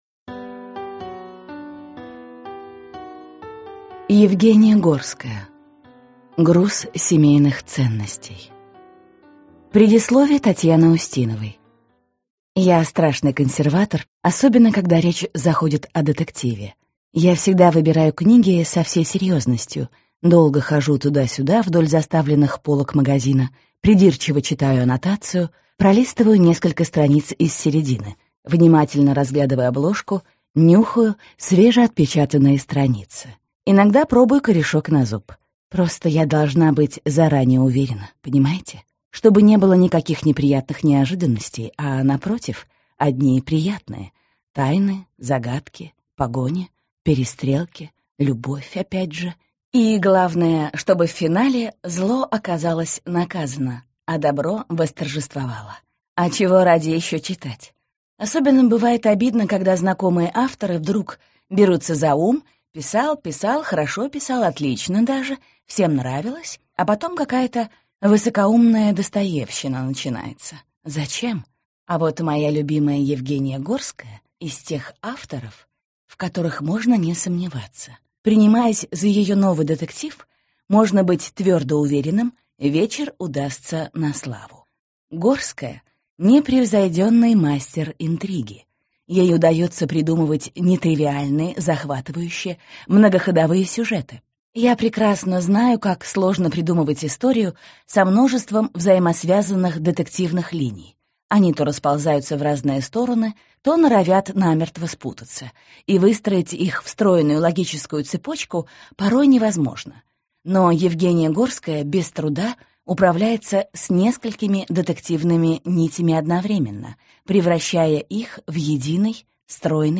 Аудиокнига Груз семейных ценностей - купить, скачать и слушать онлайн | КнигоПоиск